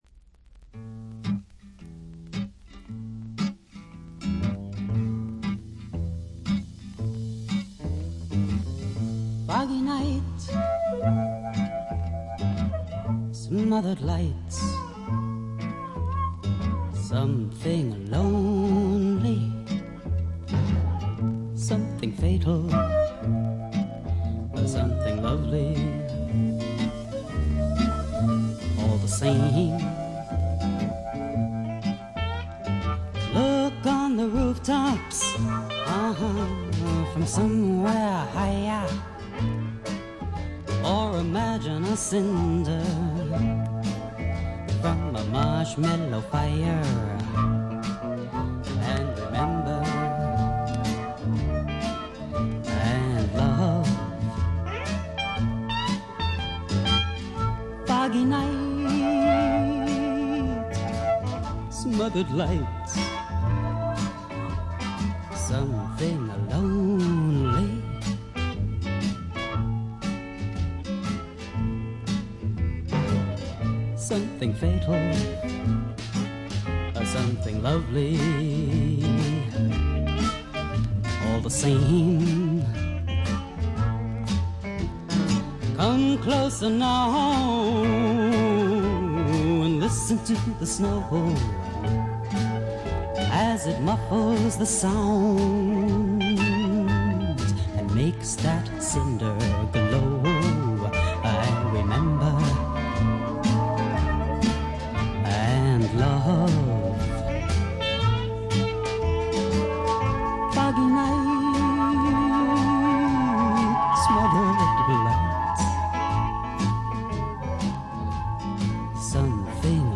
60年代気分満開の中に漂うダークでブルージーなアシッドな香りがまた良いです。
試聴曲は現品からの取り込み音源です。